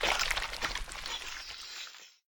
Decay1.ogg